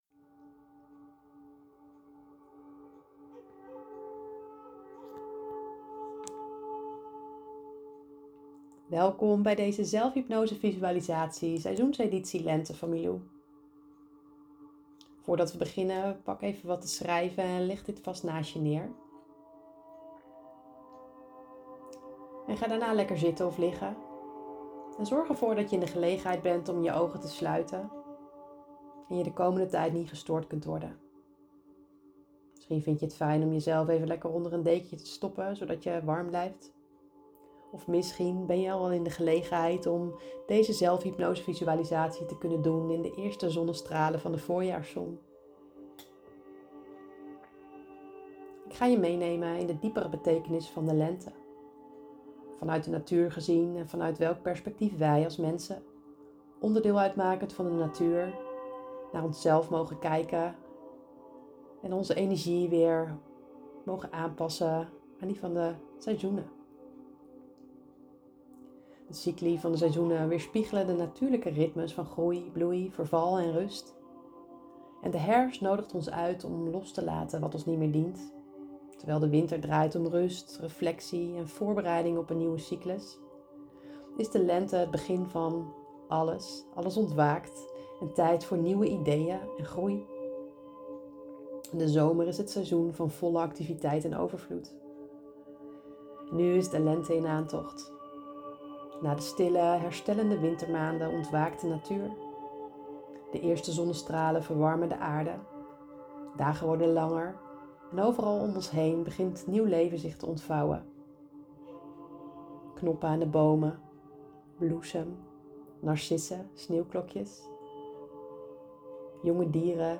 Lente-zelfhypnose-visualisatie-_1-1.mp3